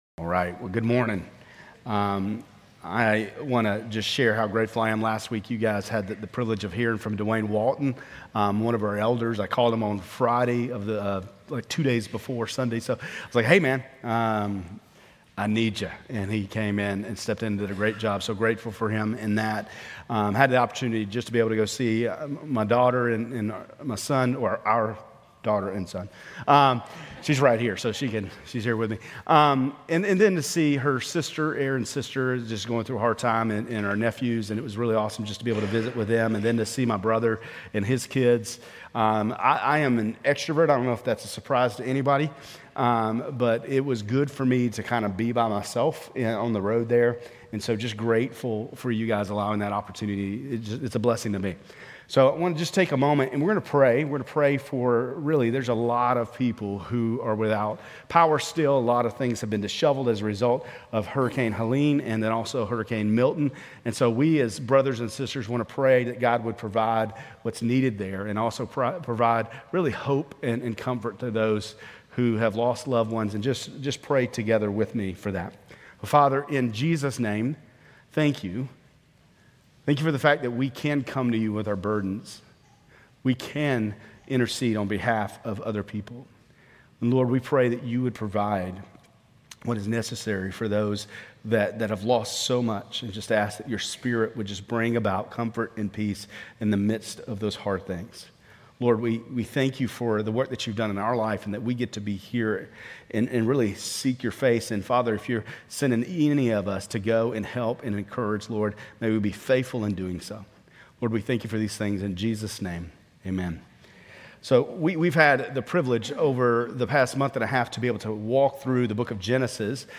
Grace Community Church Lindale Campus Sermons Genesis 12:1-3,15,17 - Abraham Oct 14 2024 | 00:28:35 1x 00:00 / 00:28:35 Subscribe Share RSS Feed Share Link Embed